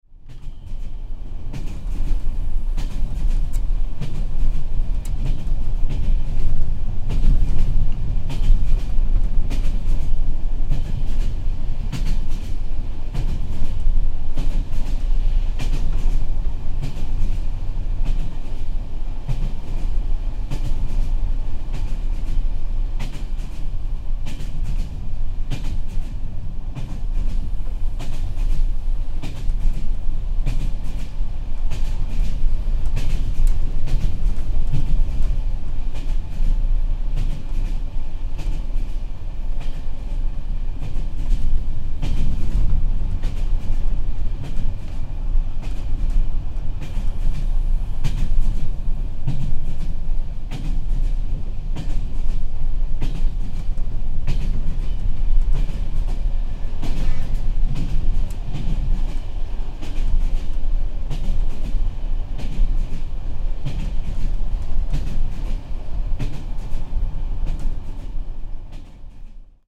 Train from Ruse to Varna